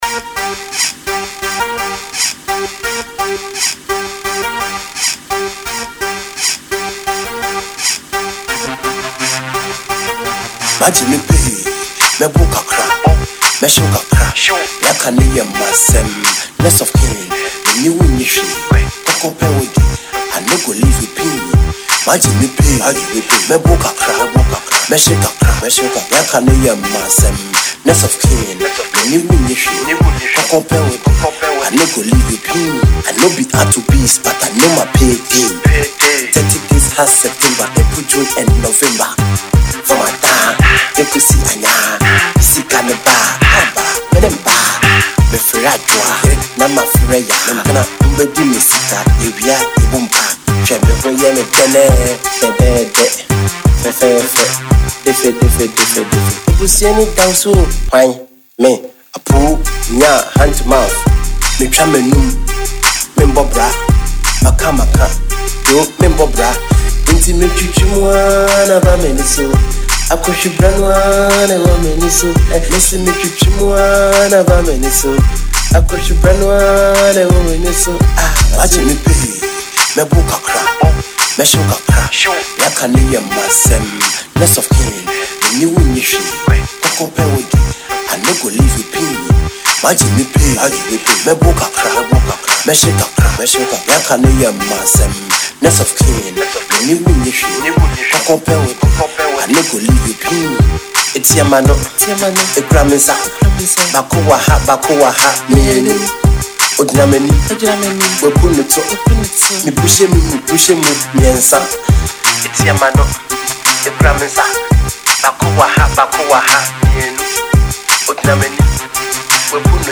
This hilarious tune is for all to enjoy.